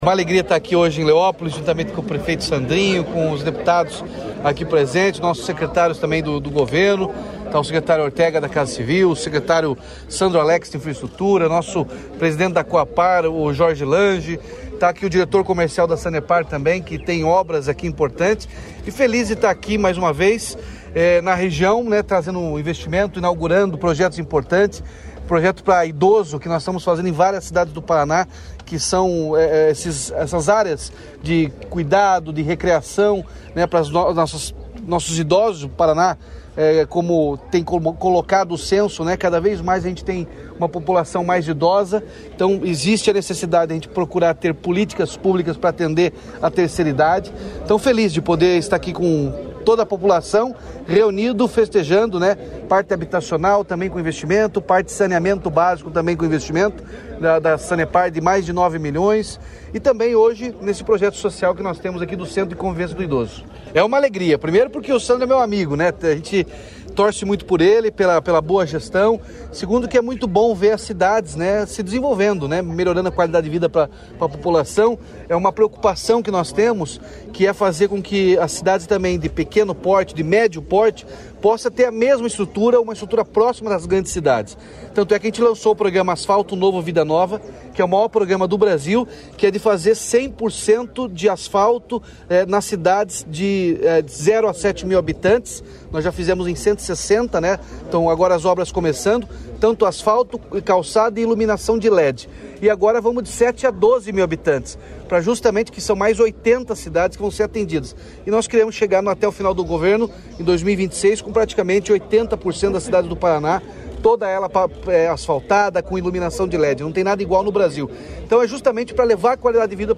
Sonora do governador Ratinho Junior sobre a entrega de rede de esgoto, centro de convivência e moradias em Leópolis